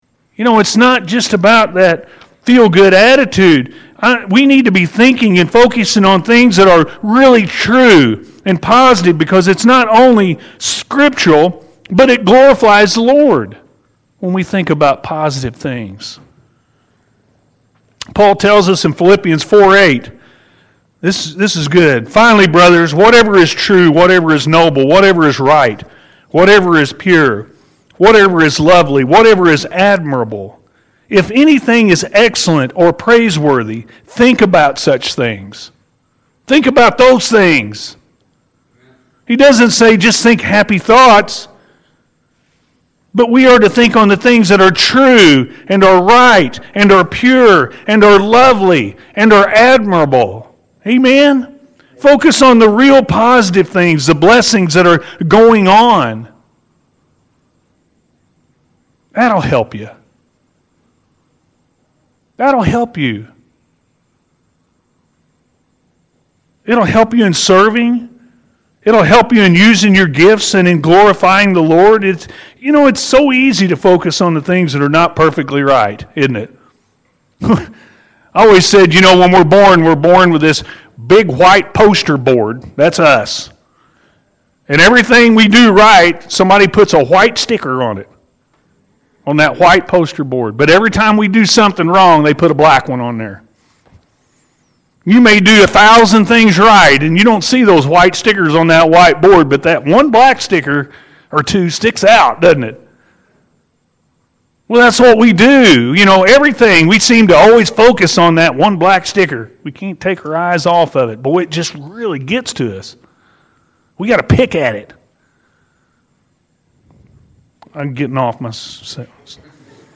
God Is Doing Good Things-A.M. Service – Anna First Church of the Nazarene